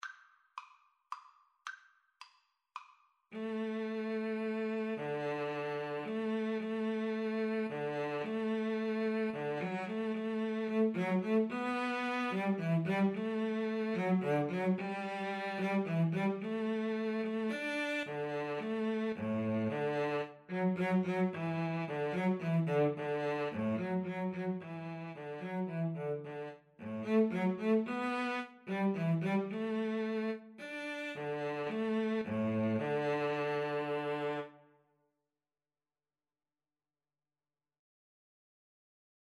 Classical (View more Classical Viola-Cello Duet Music)